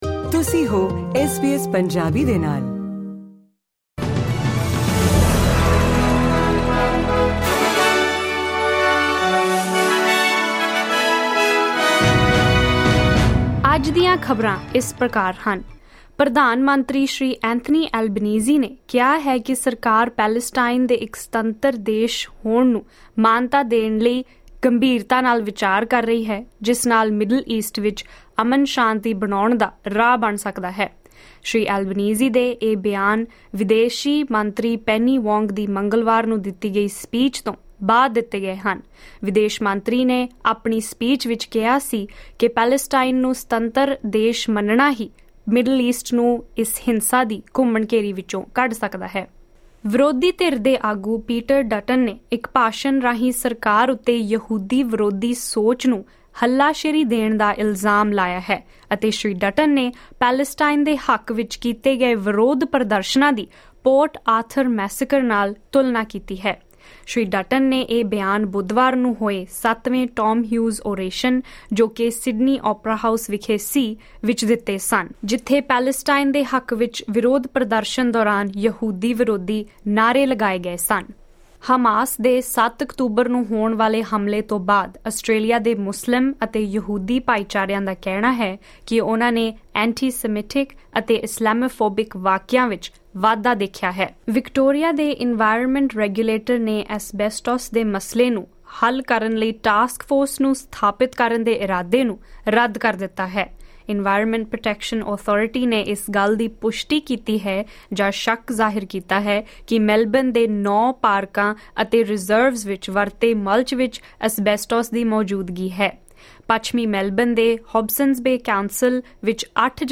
ਐਸ ਬੀ ਐਸ ਪੰਜਾਬੀ ਤੋਂ ਆਸਟ੍ਰੇਲੀਆ ਦੀਆਂ ਮੁੱਖ ਖ਼ਬਰਾਂ: 11 ਅਪ੍ਰੈਲ, 2024